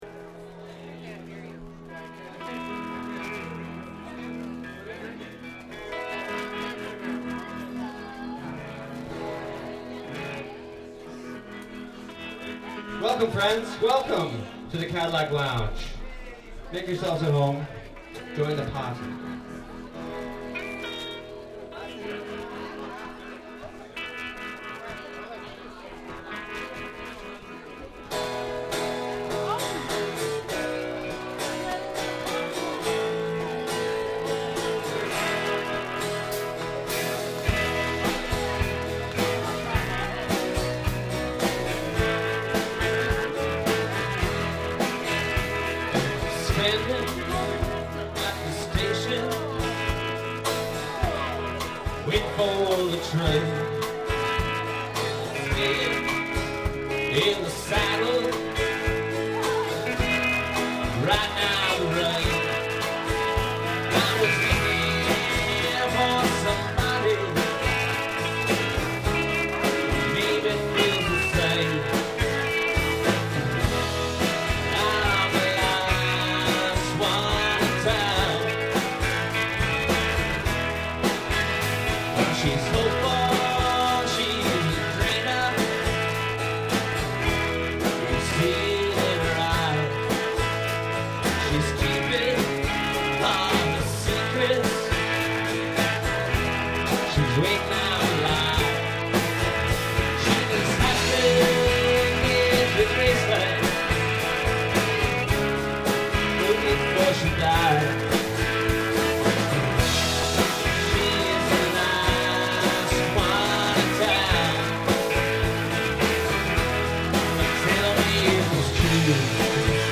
A Canadian Folk-Rock Experience
Live at the Cadillac Lounge, Toronto in January 2002